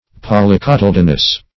Meaning of polycotyledonous. polycotyledonous synonyms, pronunciation, spelling and more from Free Dictionary.